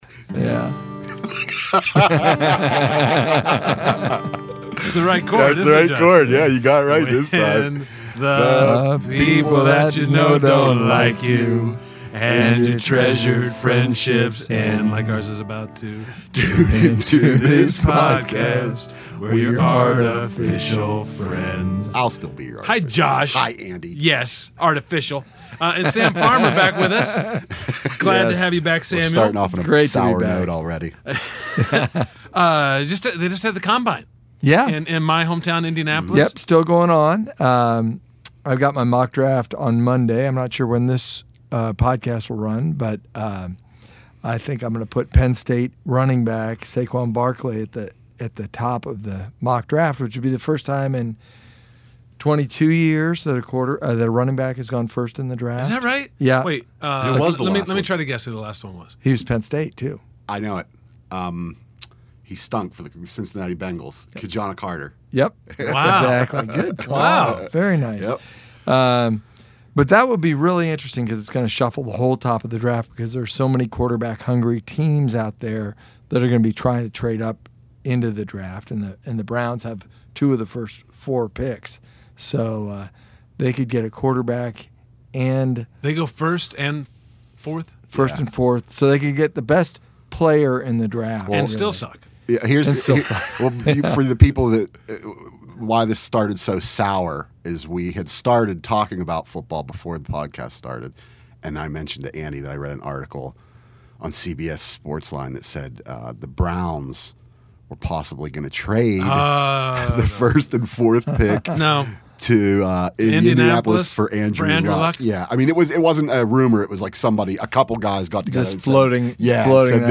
Oh, and see if you can spot the heartiest genuine laugh thus far in YAF podcast history.